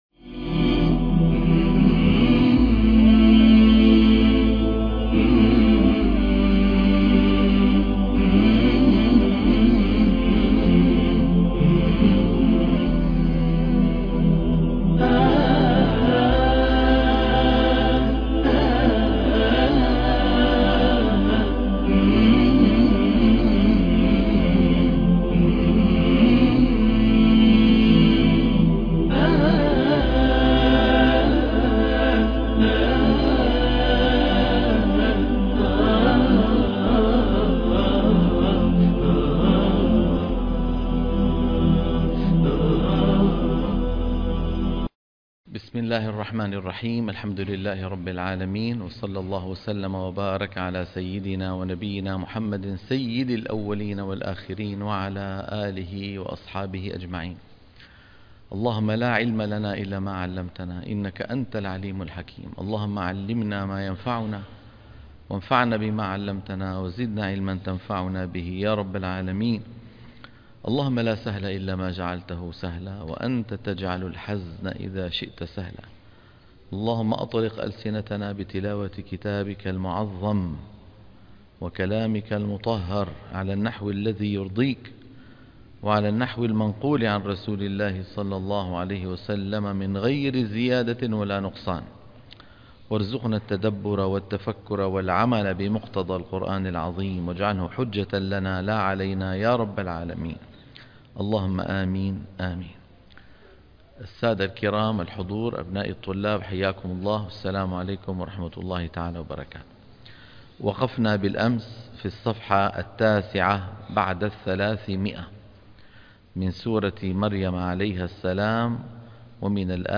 برنامج تصحيح التلاوة الحلقة - 92 - تصحيح التلاوة تلقين الصفحة 310 - الشيخ أيمن سويد